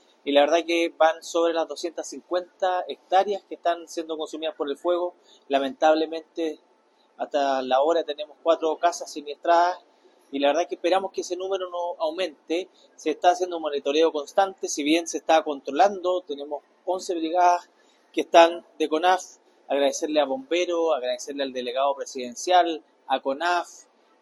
En tanto, el alcalde de Cholchol, Álvaro Labraña, entregó un resumen preliminar de los daños causados por este incendio forestal que por momentos se tornó incontrolable.
cu-alcalde-de-chol-chol.mp3